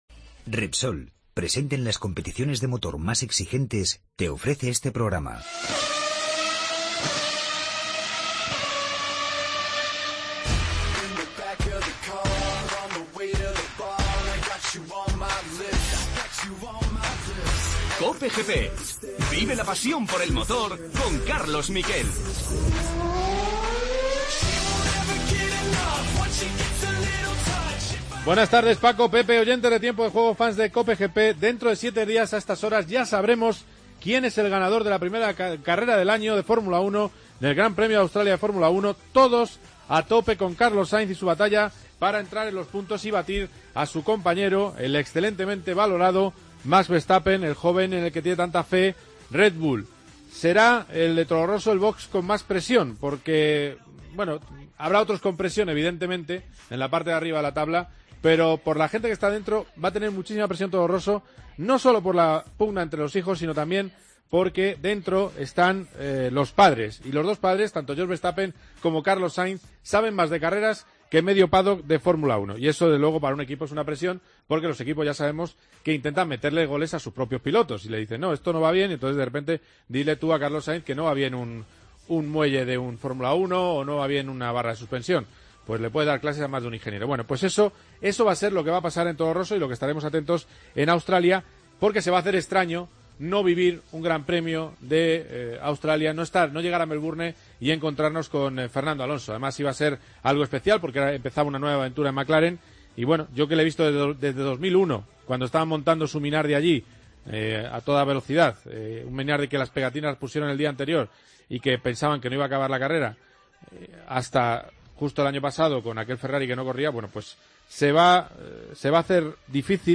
Entrevista a Andy Soucek.
Hablamos con Alex Márquez, piloto de Moto2 y vigente campeón del mundo de Moto3.